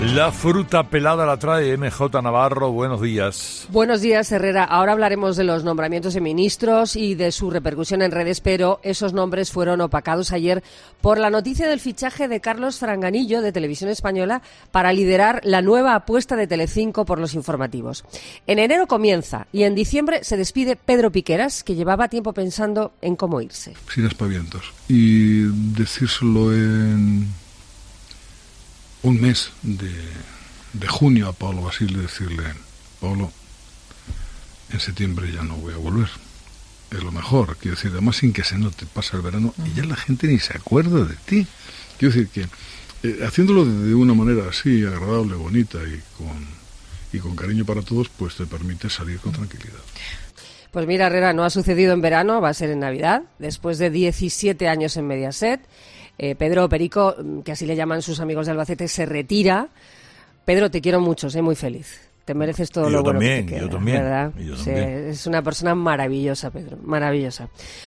En ese mismo momento, se ha reproducido un audio en el que Piqueras explicaba cómo sería su renuncia, una circunstancia que ya tiene fecha.